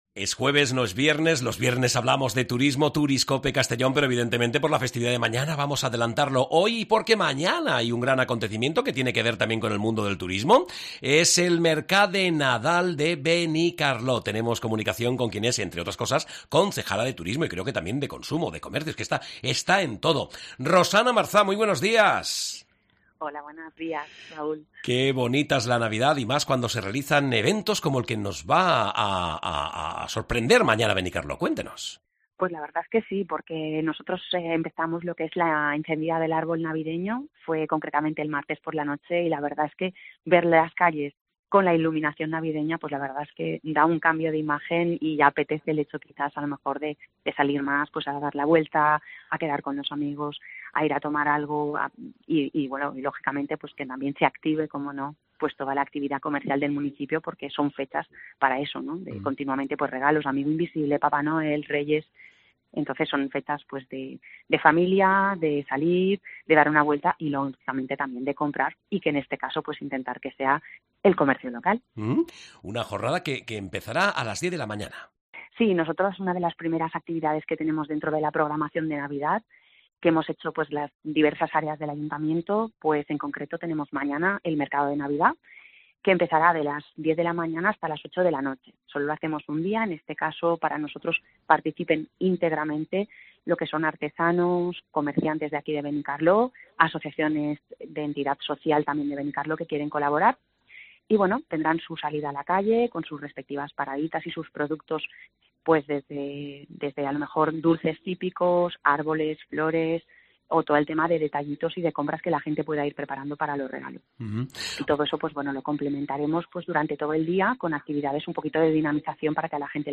AUDIO: Parlem amb la regidora de Mercats, Rosana Marzá, del Mercat de Nadal que es celebra aquest divendres a Benicarló.